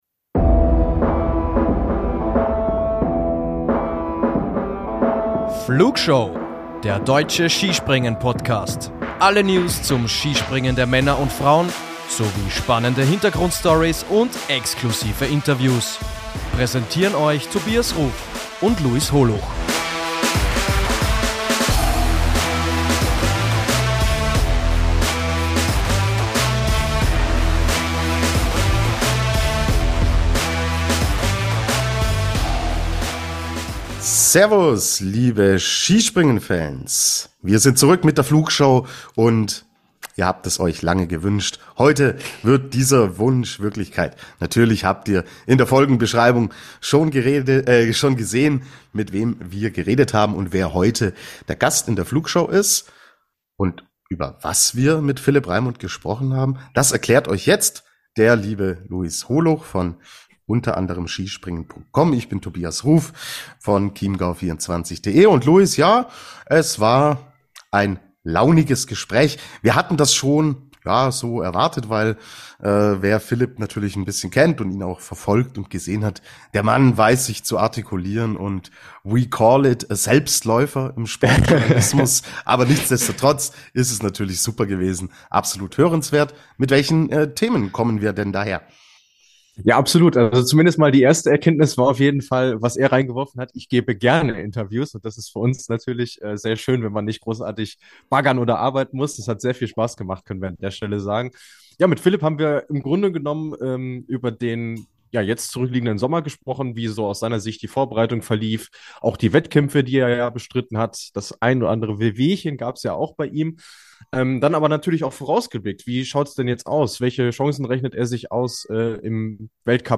Philipp Raimund im Interview ~ Wintersport Podcast